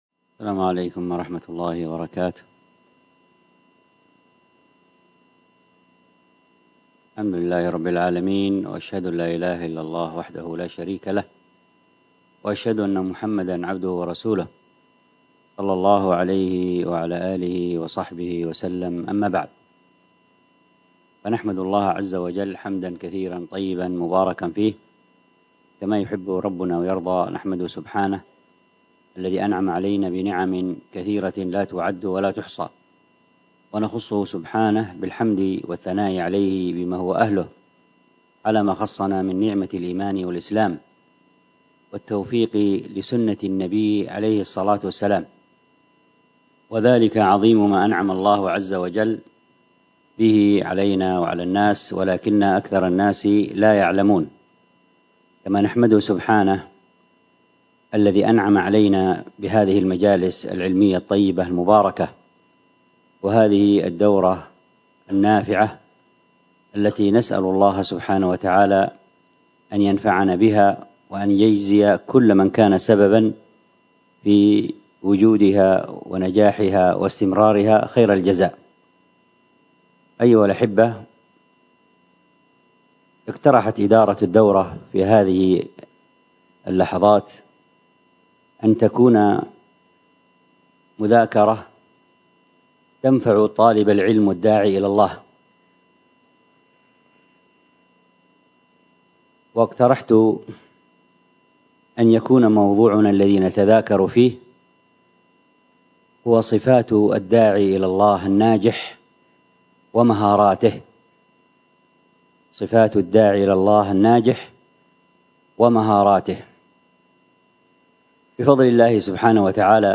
الدرس في شرح تحفة الأطفال 10، الدرس العاشر:في المثلين من:(30ـ إن في الصفات والمخارج اتفق*حرفان فالمثلان فيهما أحق...34ـ أو حرك الحرفان في كل فقل*كل كبير وافهمنه بالمثل).